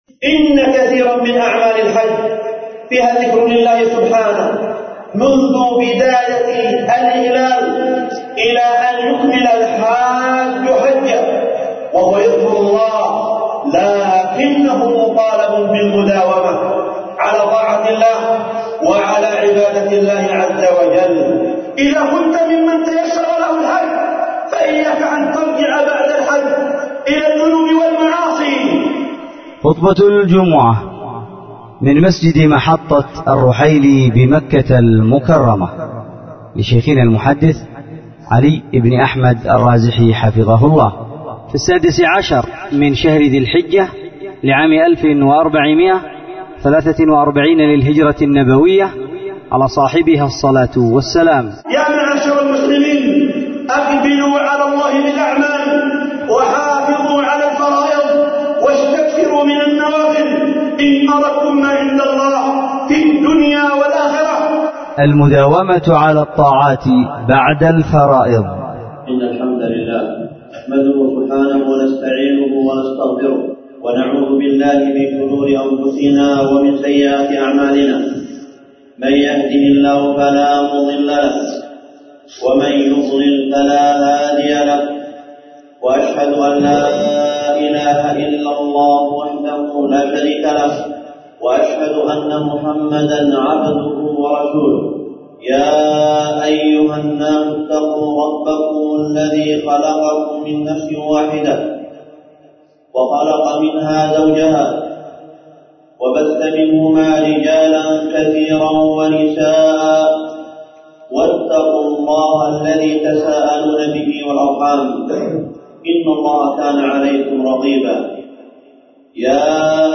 خطبة جمعة من مسجد محطة الرحيلي بمكة المكرمة